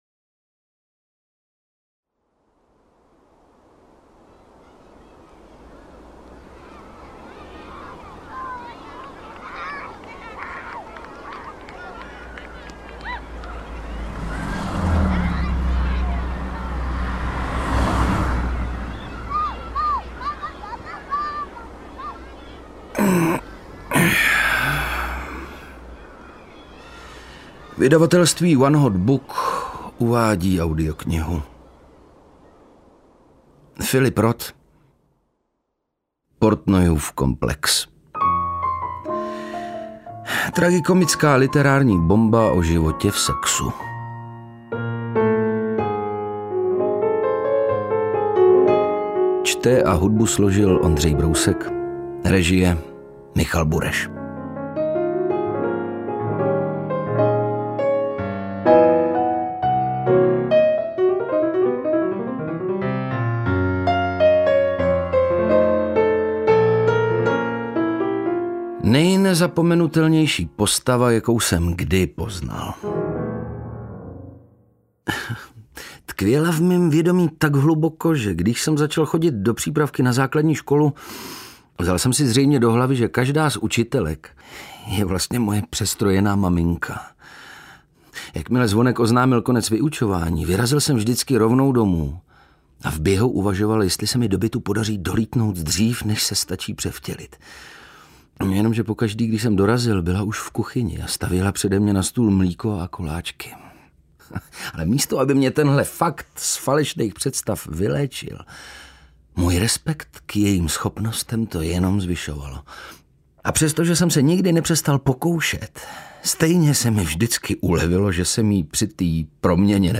Interpret:  Ondřej Brousek
AudioKniha ke stažení, 29 x mp3, délka 9 hod. 10 min., velikost 503,1 MB, česky